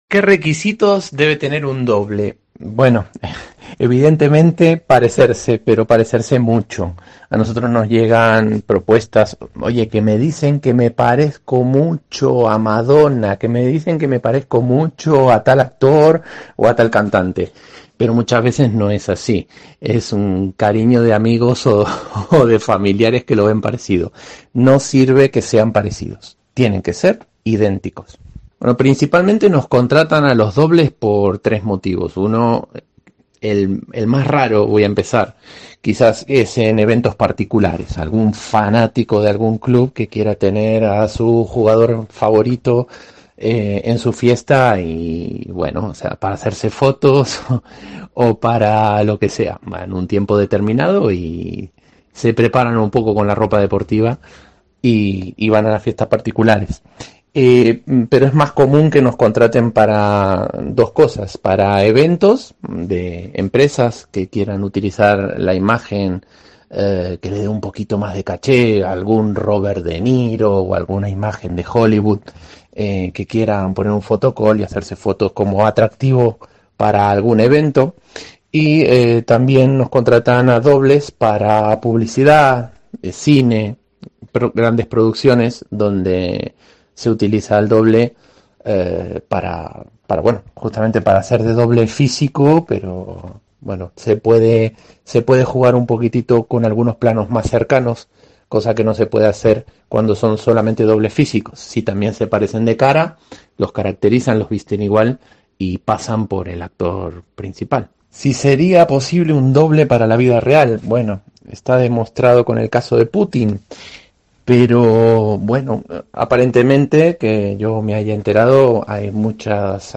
Hablamos con un experto sobre los requisitos que debe cumplir un doble y cómo algunos actores de Hollywood los utilizan para despistar en algunos actos